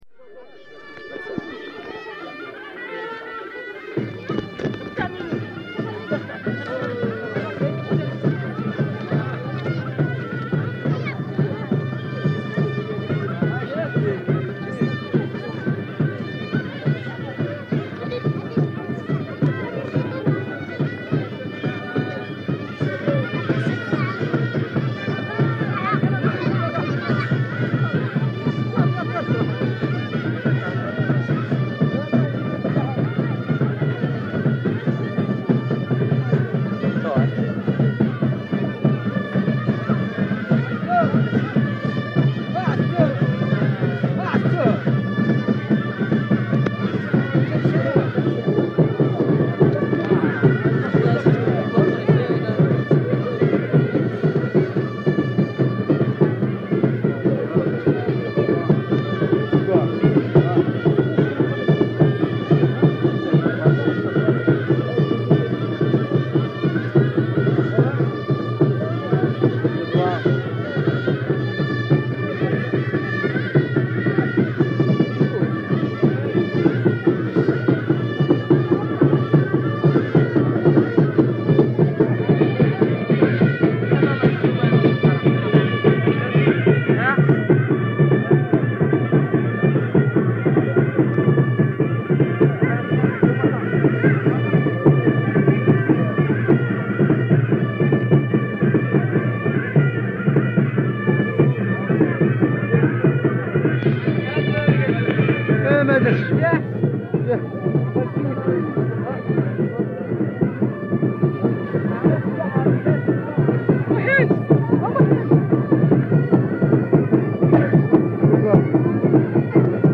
From the sound collections of the Pitt Rivers Museum, University of Oxford, being from a collection of reel-to-reel tape recordings of Berber (Ait Haddidu) music and soundscapes made by members of the Oxford University Expedition to the Atlas Mountains of Southern Morocco in 1961.